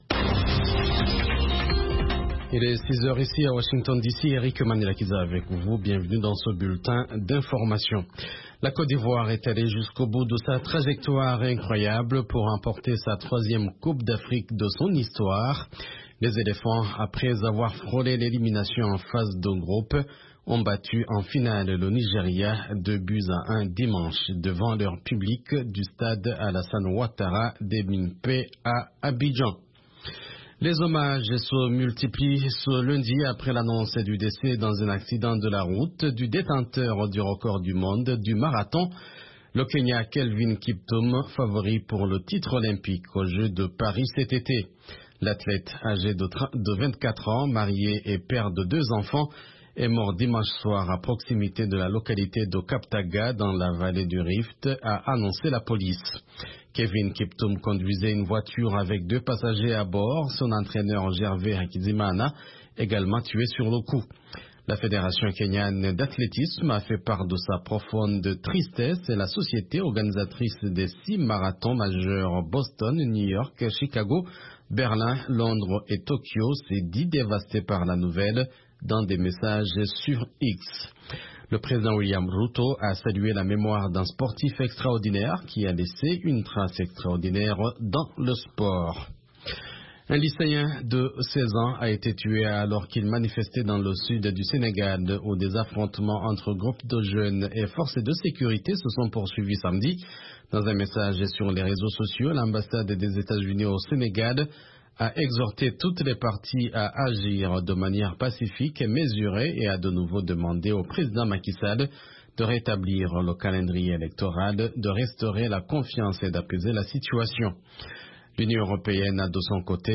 Bulletin d’information de 15 heures
Bienvenu dans ce bulletin d’information de VOA Afrique.